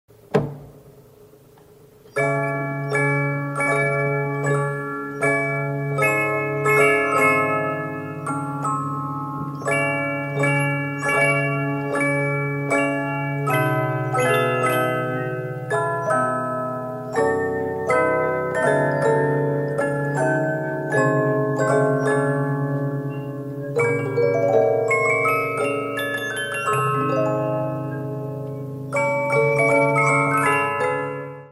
Cartel Nicole Frère dit "à ouverture", vendu par la maison Wurtel de Paris.
Grâce à un cylindre de grand diamètre, les morceaux sont beaucoup plus long, la dédiant particulièrement aux ouvertures d'Opéra.